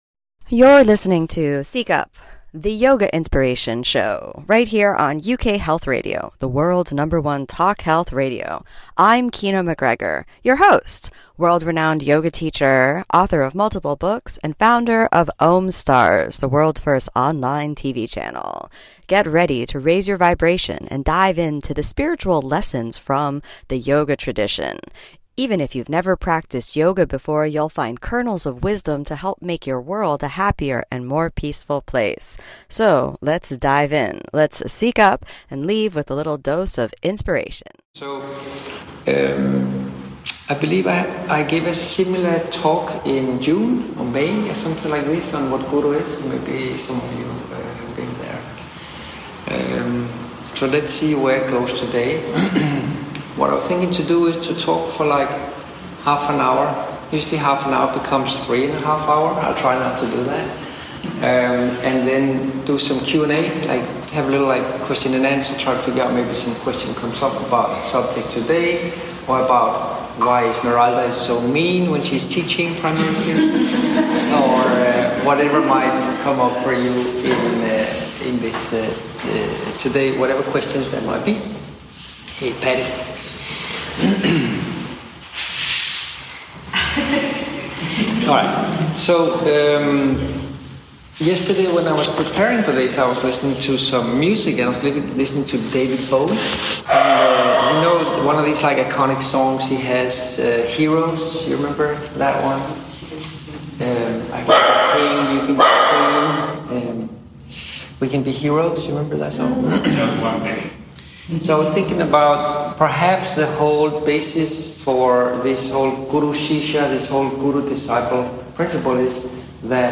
Join Kino MacGregor, one of the world’s master yoga teachers, as she shares her yoga life hacks to translate the wisdom of yoga into a happier, more peaceful, more loving life. Listen to authentic, raw conversations and talks from Kino on her own and with real students about what yoga is really all about.